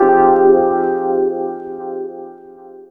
07 pEPtide 165 G.wav